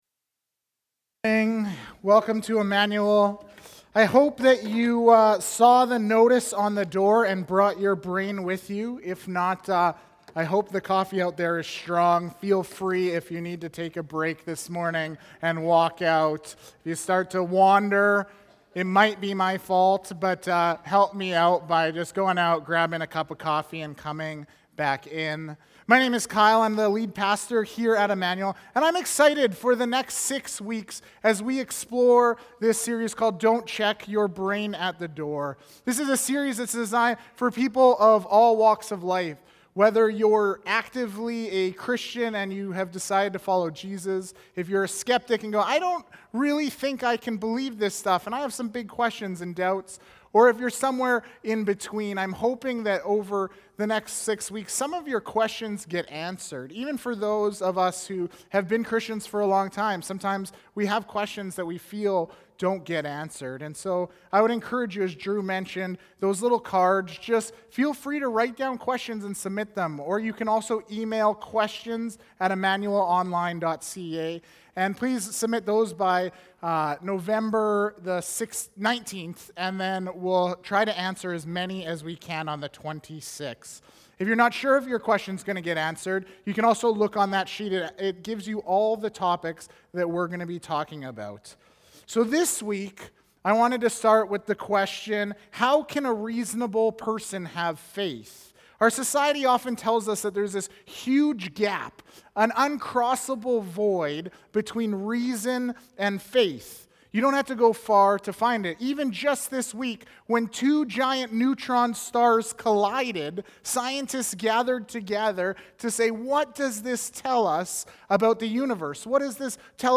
Immanuel Church Sermons | Immanuel Fellowship Baptist Church